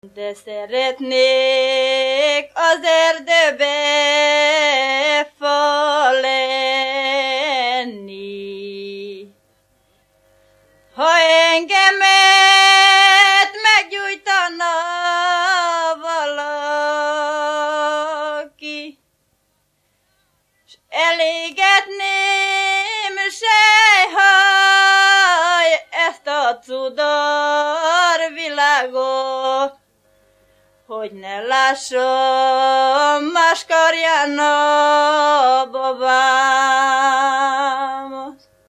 Erdély - Kolozs vm. - Visa
Műfaj: Akasztós
Stílus: 8. Újszerű kisambitusú dallamok